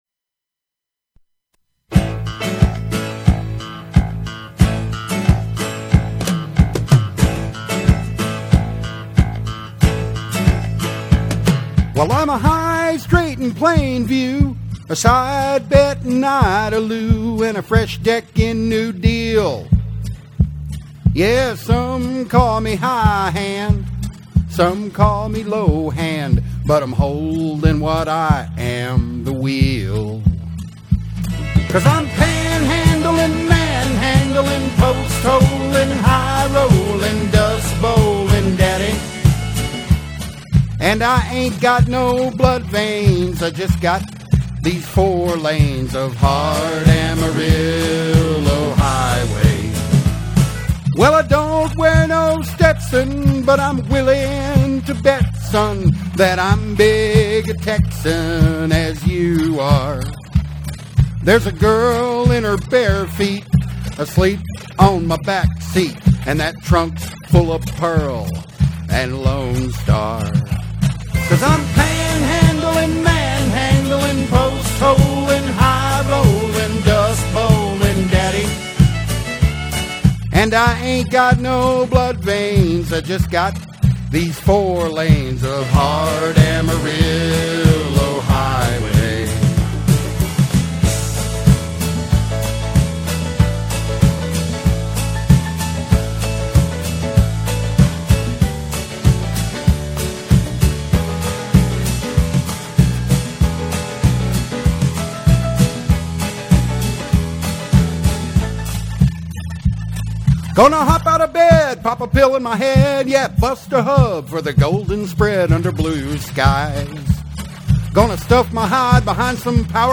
EDIT: This comes close, and I even sing it: